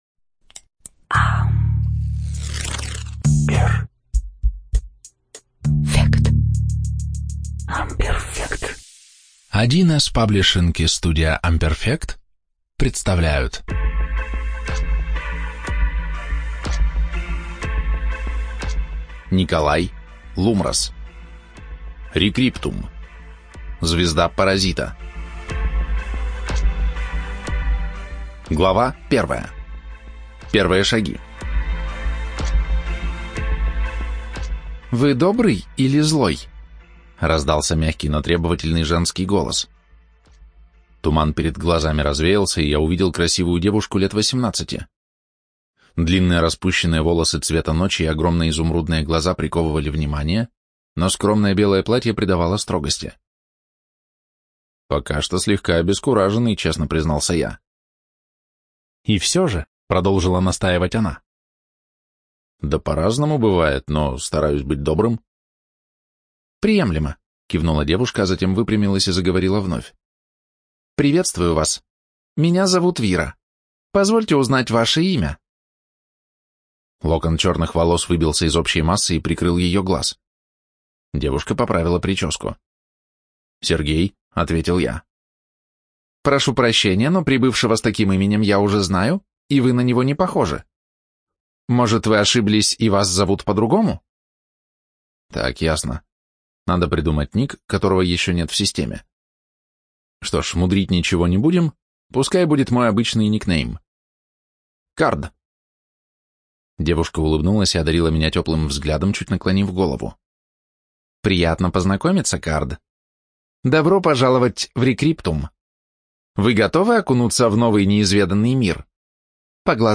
Студия звукозаписи1С-Паблишинг